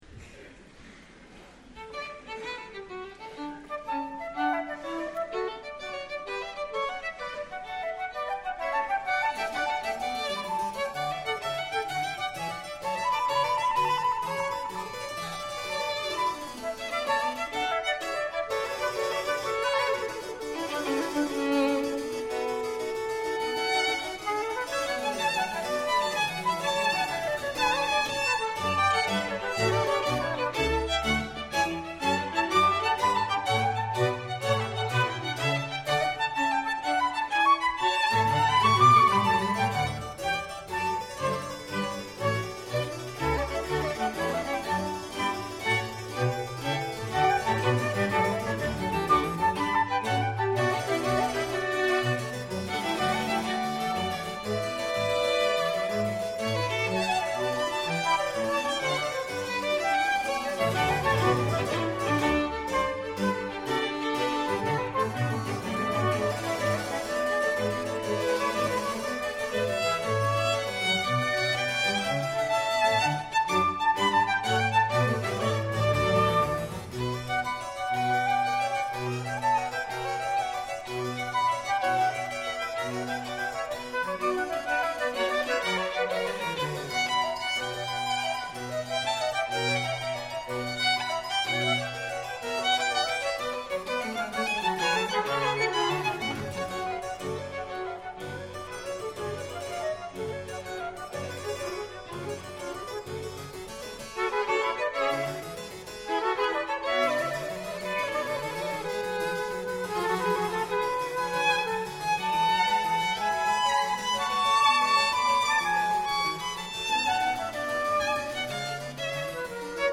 Clavicembalo
Reg. dal vivo, Sala Grande del Conservatorio "G.Verdi" di Milano, 18/11/2000
3_tempo_allegro.mp3